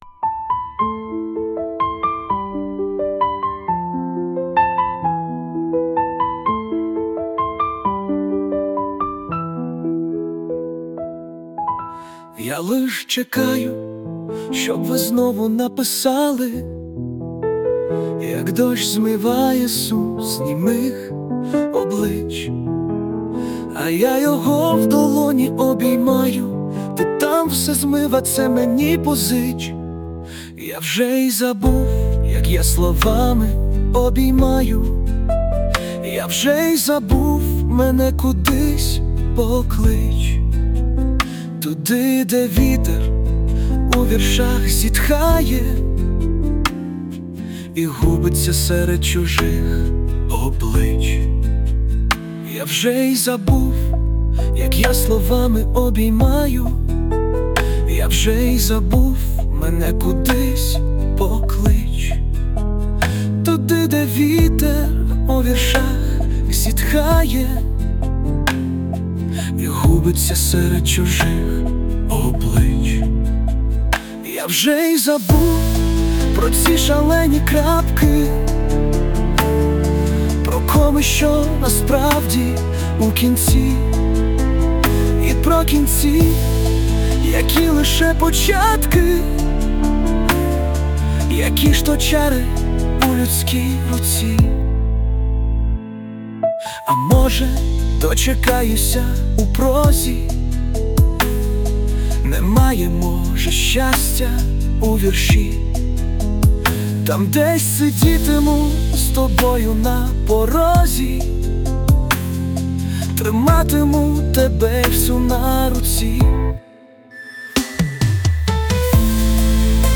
Музичне прочитання з допомогою ШІ
СТИЛЬОВІ ЖАНРИ: Ліричний